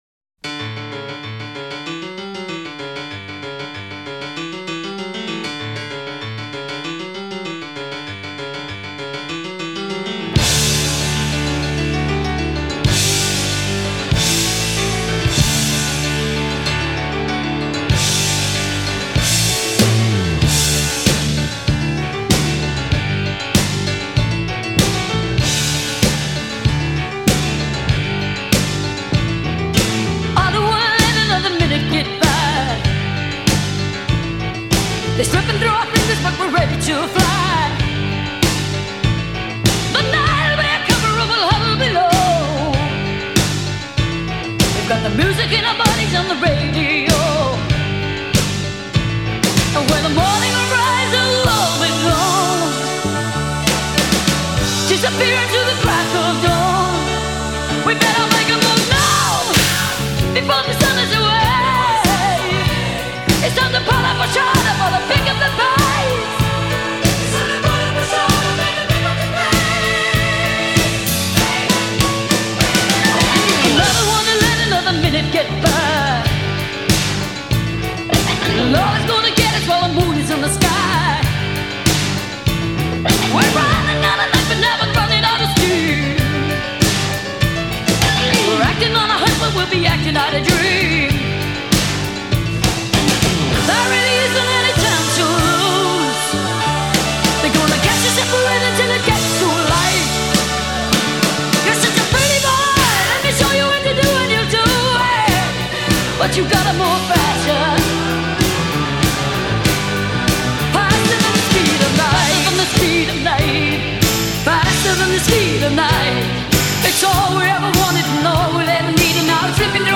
после чего её голос приобрел лёгкую хрипотцу.